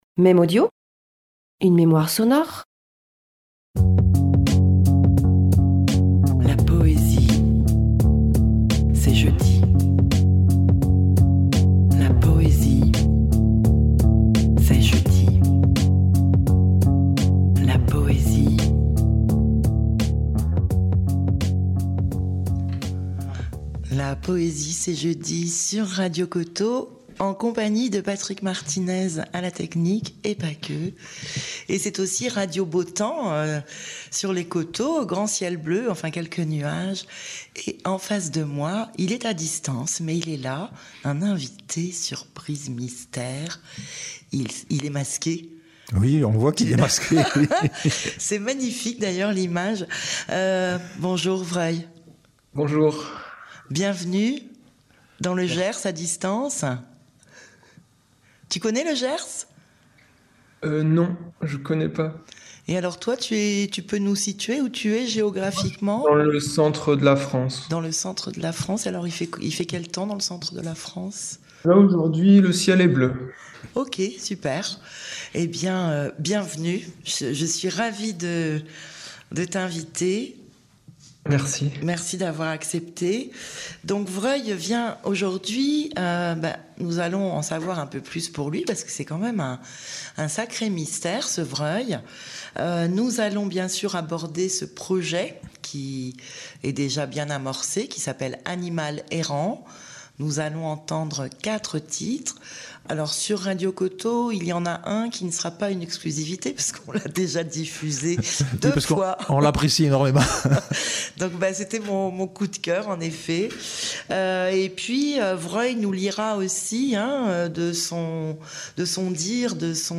Emission radio